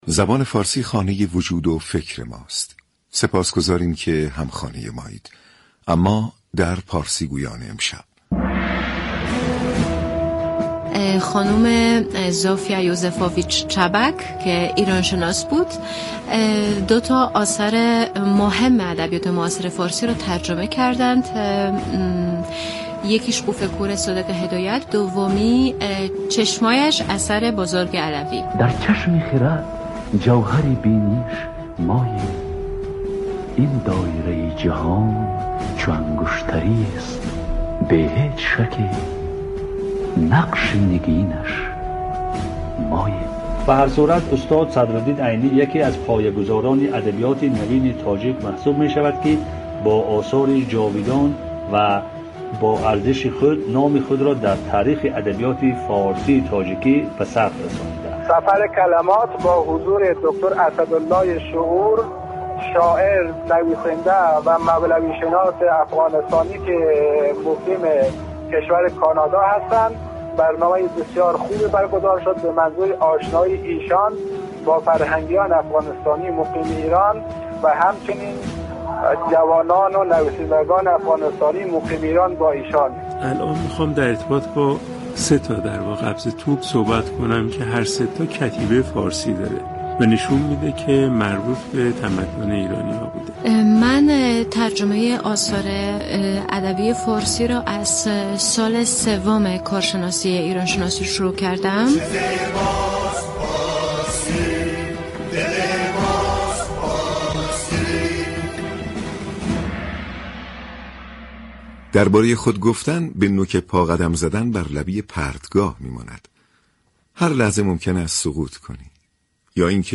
این گفت و گو را در ادامه می شنوید : و/م دریافت فایل شخصیت مهم خبری